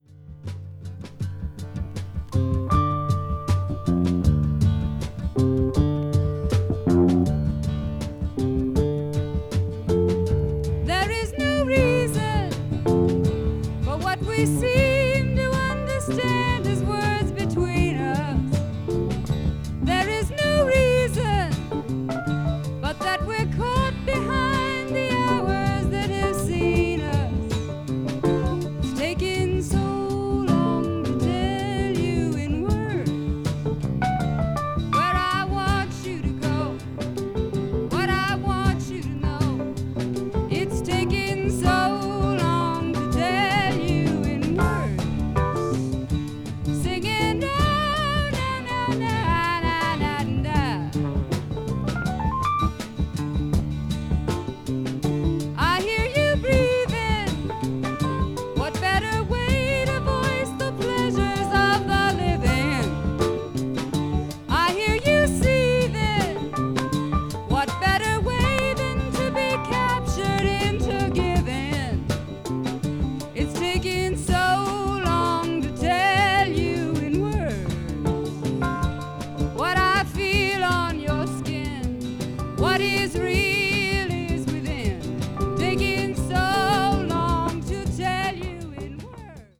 A1終盤からA2中盤にかけてスリキズが1本あり、プチノイズが入る箇所があります。